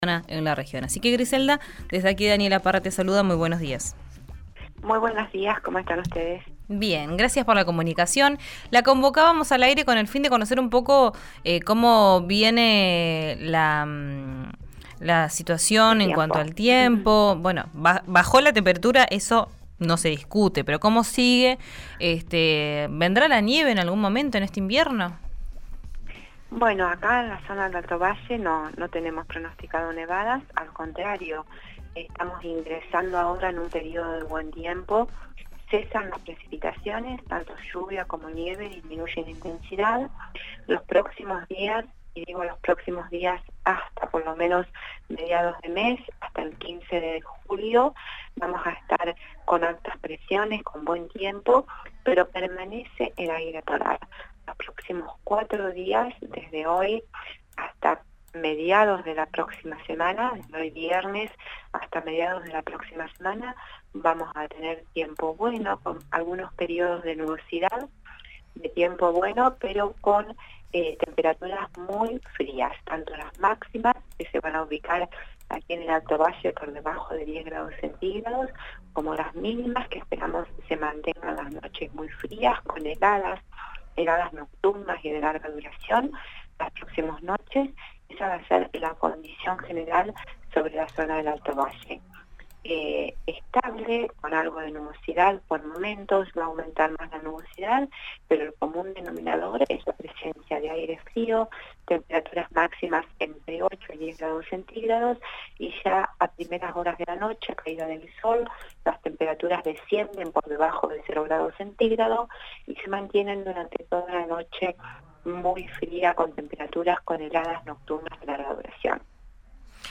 integrante de la AIC en dialogo con Río Negro RADIO.